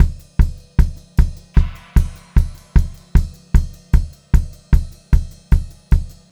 152SPCYMB3-L.wav